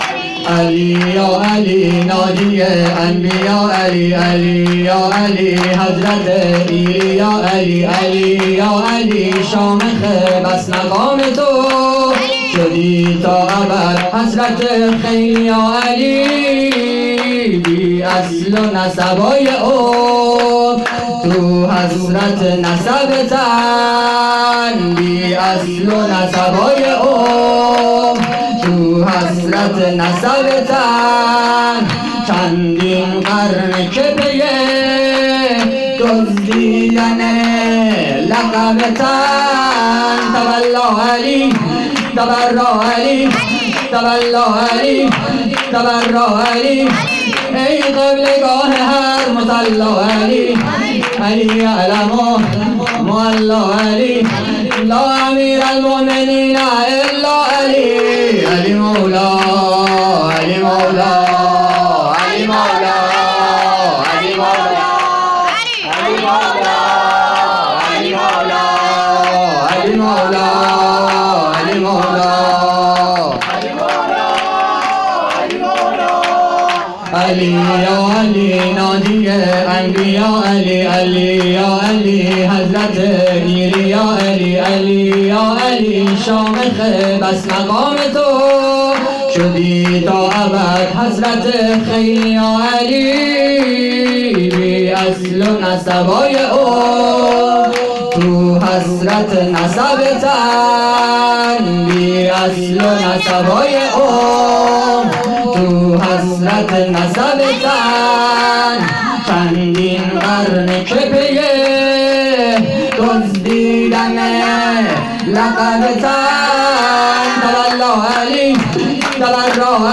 سرود امیرالمومنین امام علی(ع)